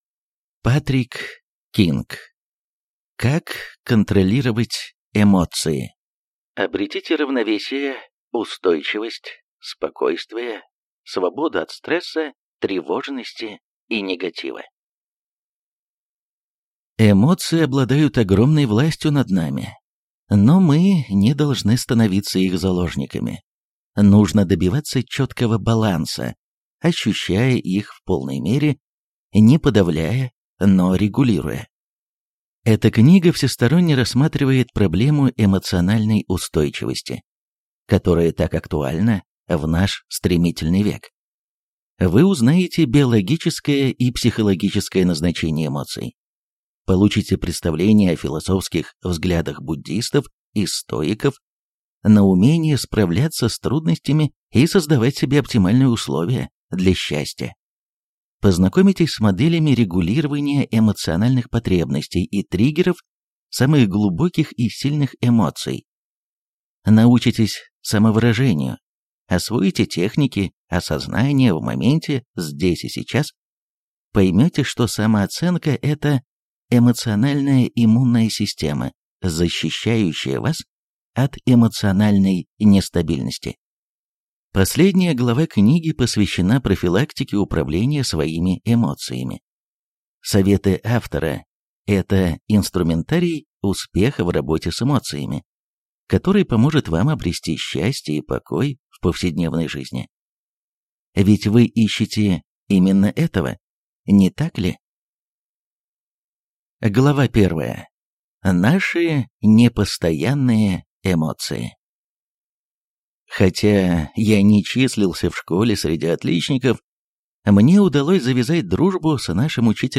Аудиокнига Как контролировать эмоции. Обретите равновесие, устойчивость, спокойствие, свободу от стресса, тревожности и негатива | Библиотека аудиокниг